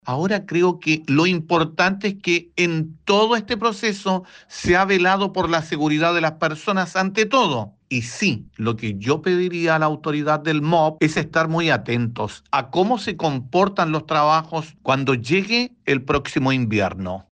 En tanto, su par, Sandro Puebla, recalcó la necesidad de mantener una vigilancia permanente del comportamiento del sector, especialmente de cara al próximo invierno, considerando las condiciones climáticas y el impacto que estas podrían tener en la zona.
cu-kandinsky-concejal-sandro-puebla-1.mp3